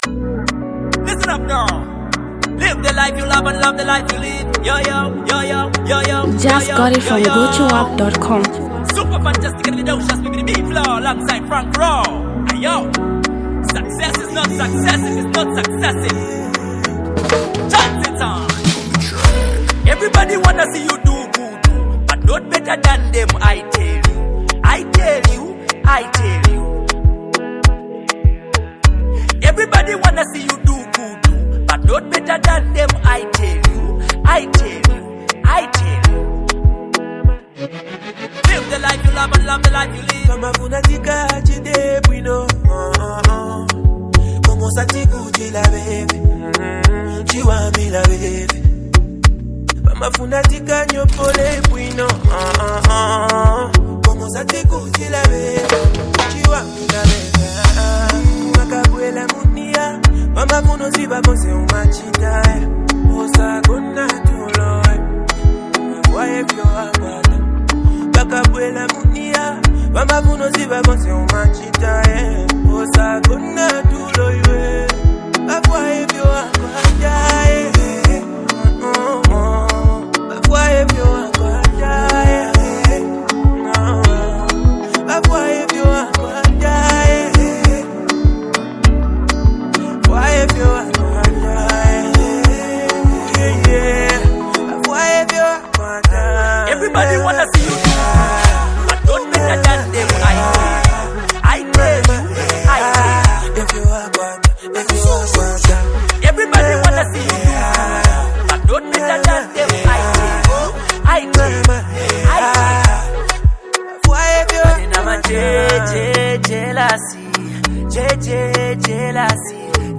Zambian Mp3 Music
new buzzing street jam